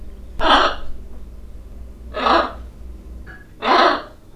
Red-and-green Macaw
Ara chloropterus